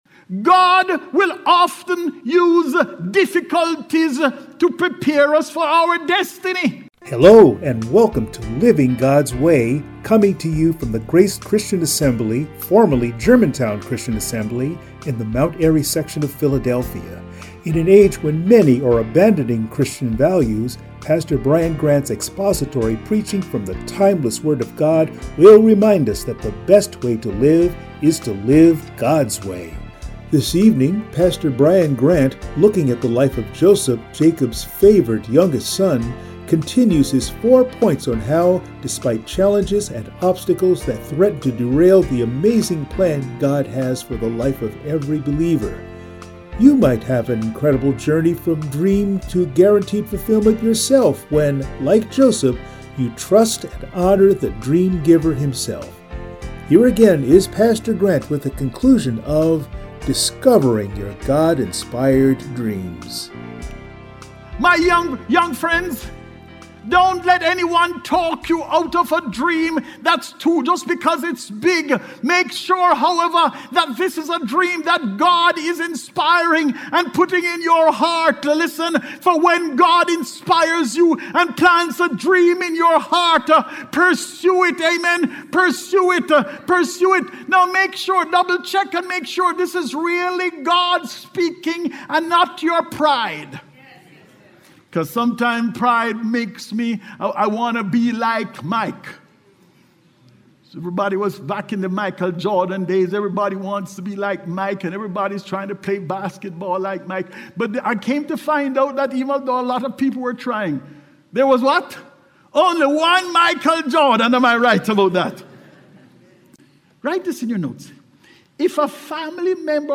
Passage: Genesis 37:1-11 Service Type: Sunday Morning